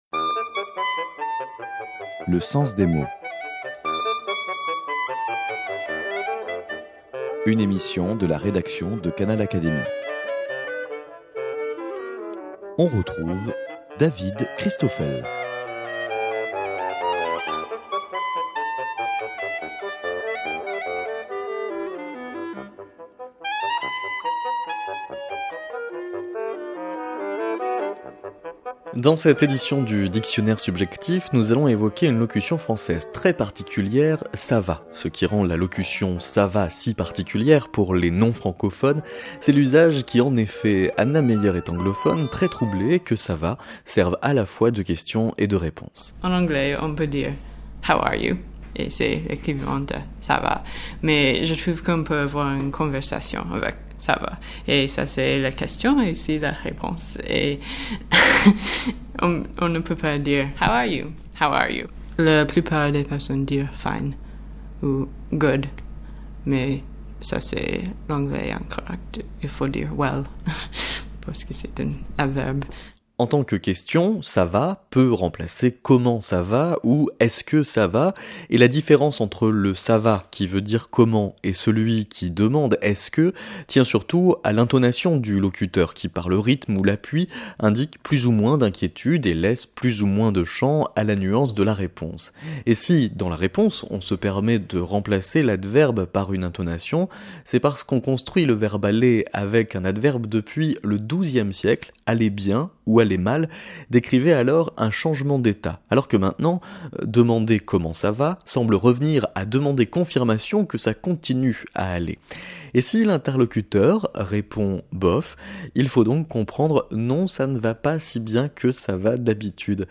journaliste